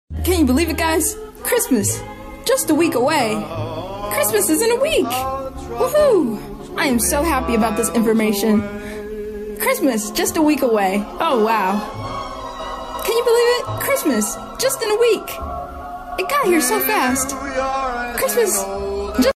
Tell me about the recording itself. Christmas is just 1 week away! Sorry about the quality, had to make this on my phone really quick.